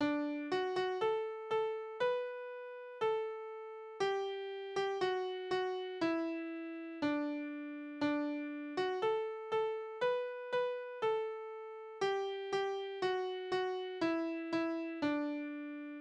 Tonart: D-Dur
Taktart: 4/4
Tonumfang: große Sexte